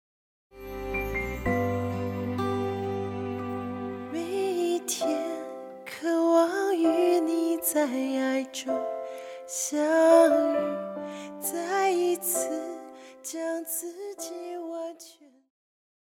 套鼓(架子鼓)
乐团
教会音乐
演奏曲
独奏与伴奏
有主奏
有节拍器